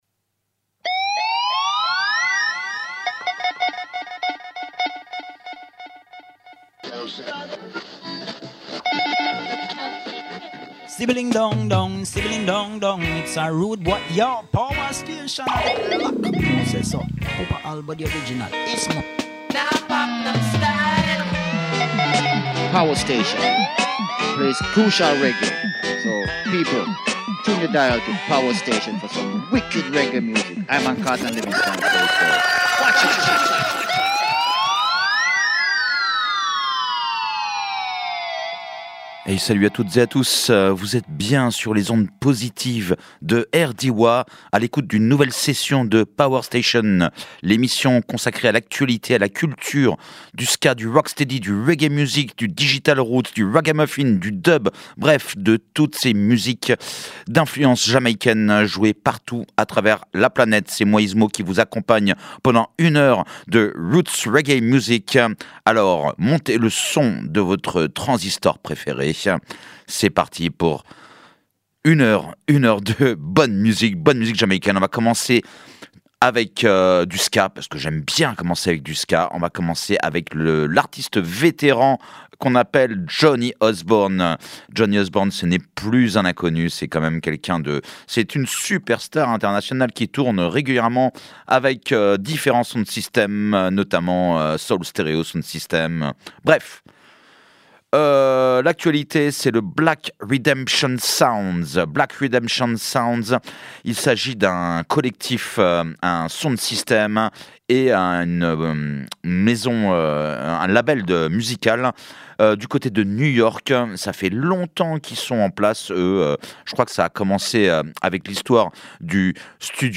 reggae , ska